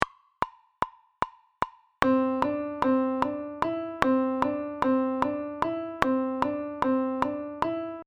Ejemplo de compás de 5x8 con la medida de 2+3.
Audio de elaboración propia. Subdivisión métrica del compás 5x8 en 2 + 3. (CC BY-NC-SA)
COMPAS-5x8-2.mp3